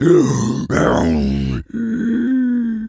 tas_devil_cartoon_09.wav